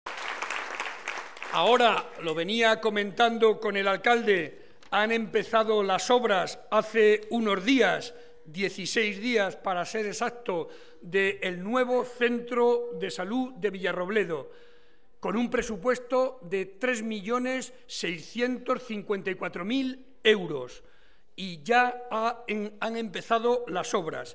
El presidente de Castilla-La Mancha y candidato a la reelección, José María Barreda, protagonizó esta tarde un acto público en la Casa de la Cultura de Villarrobledo, donde se dieron cita más de 500 personas a las que quiso agradecer, en primer lugar, el cariño con el que le recibieron.